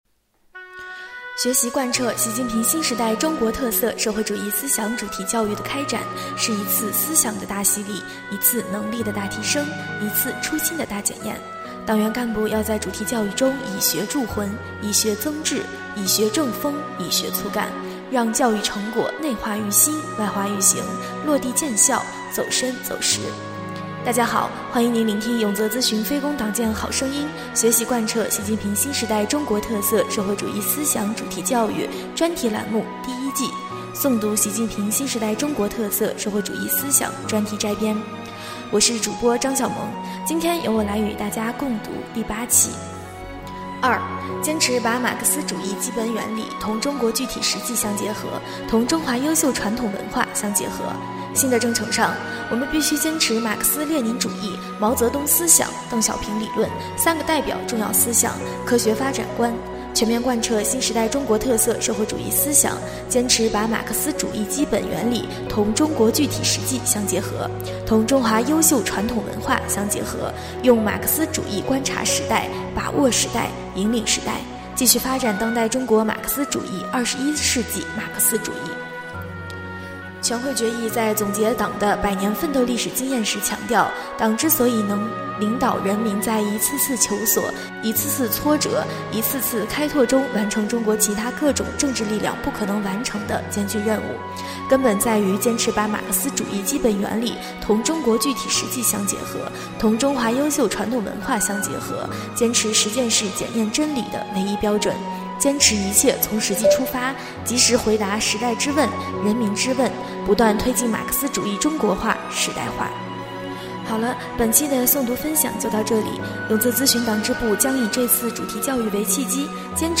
【诵读】《习近平新时代中国特色社会主义思想专题摘编》第8期-永泽党建